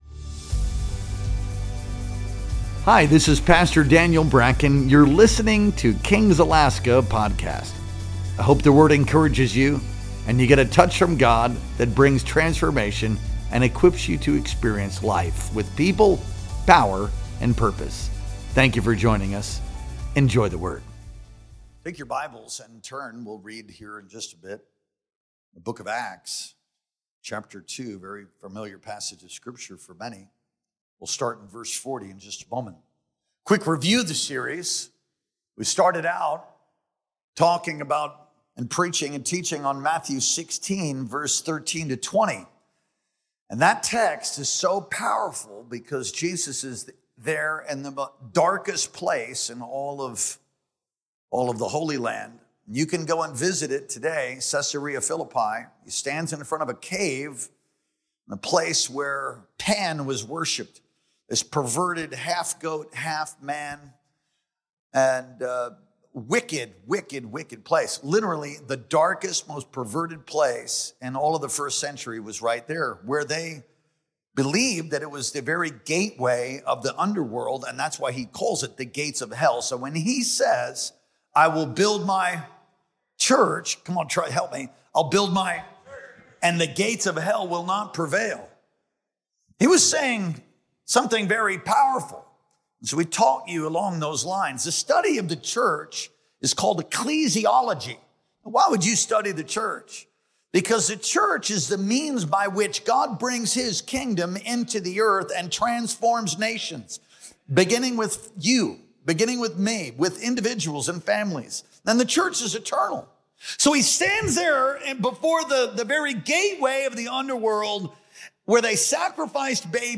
Our Sunday Morning Worship Experience streamed live on September 7th, 2025.